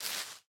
sounds / block / moss / step6.ogg